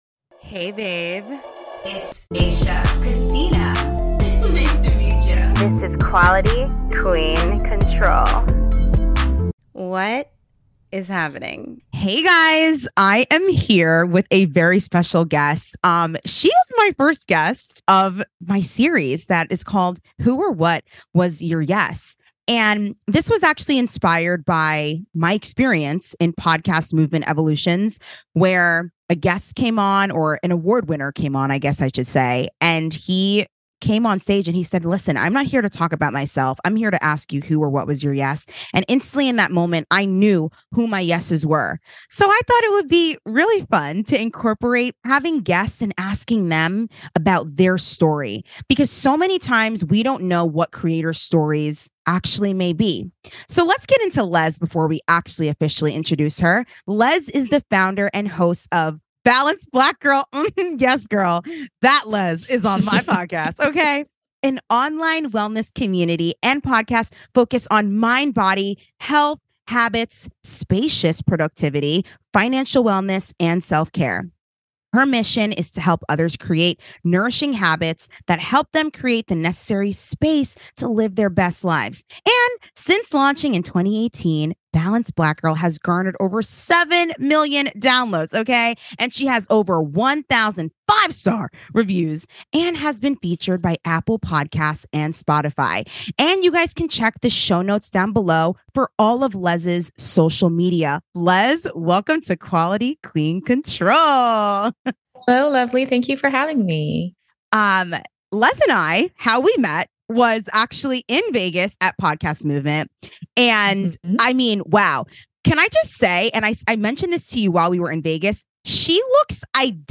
This series was inspired by my experience at podcast movement in March, and I wanted to take the time to interview people and figure out their story and allow them to tell it with thought provoking questions!